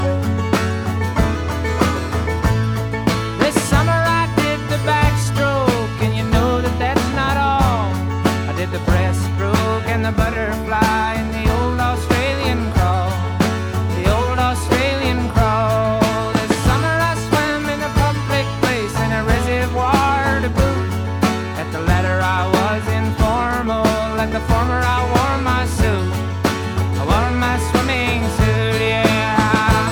Contemporary Folk
Жанр: Рок